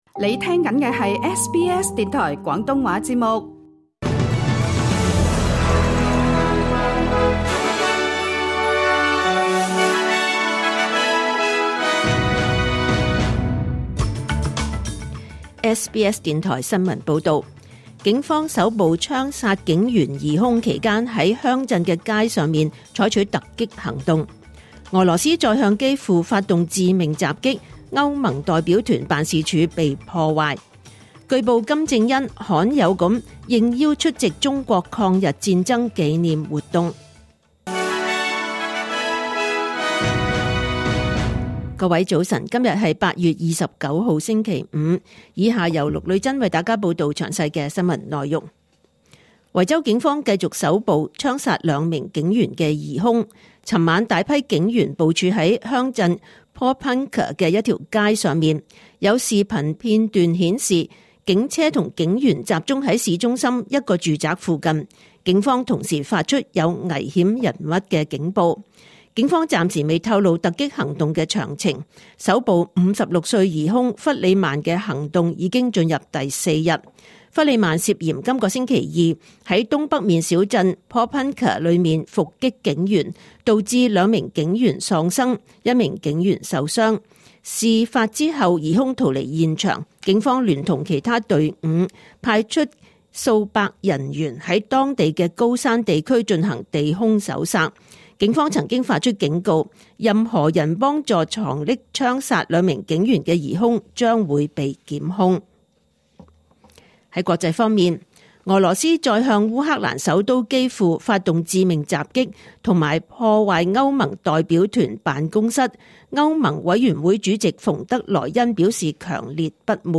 2025年8月29日 SBS 廣東話節目九點半新聞報道。